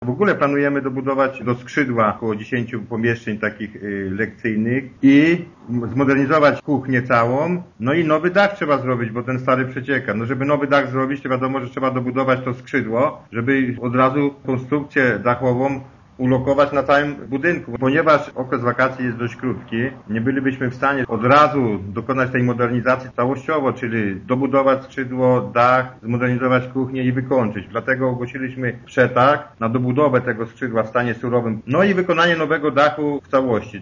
Reszta prac będzie realizowana później” - zapowiada wójt Filipczak: